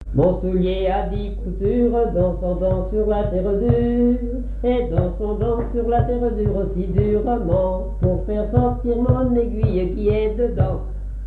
danse : branle
Genre énumérative
Pièce musicale inédite